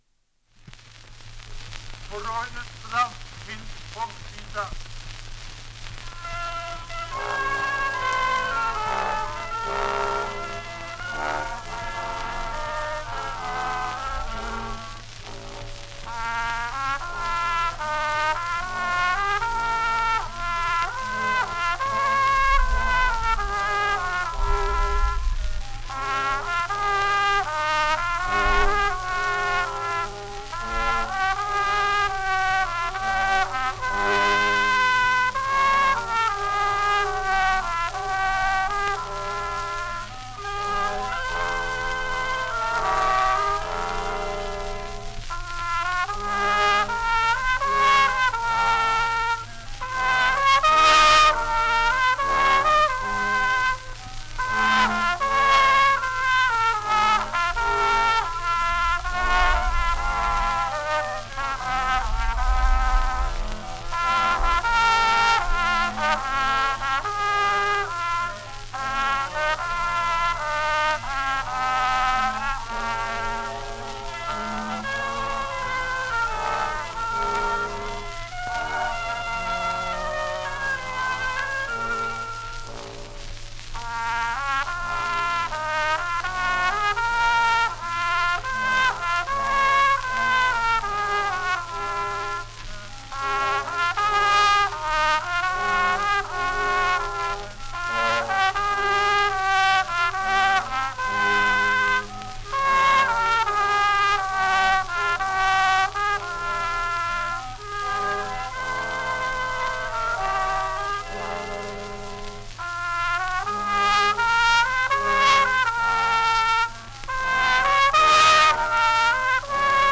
På Roines strand. Finsk folkvisa
Ljudinspelningar från omkring 1900